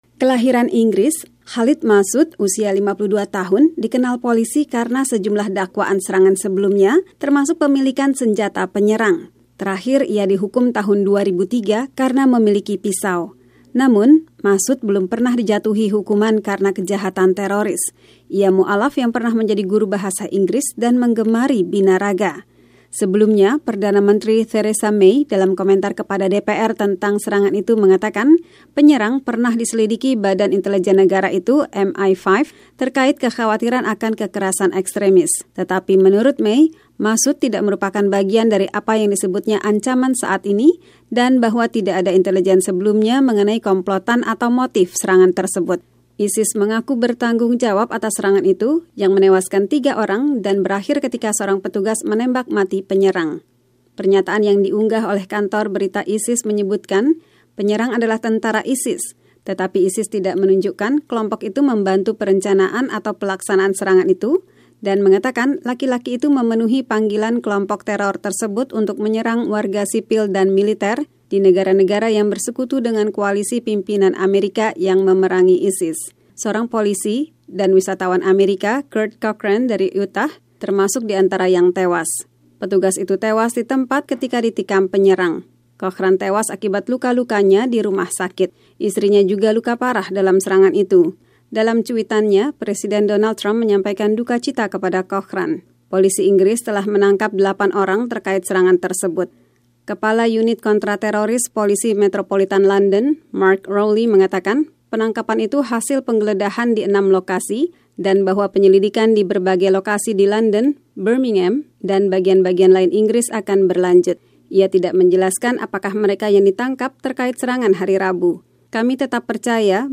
Polisi menangkap delapan orang terkait serangan di London dan mengidentifikasi pelaku serangan di dekat gedung Parlemen Inggris itu hari Rabu sebagai Khalid Masood. Laporan wartawan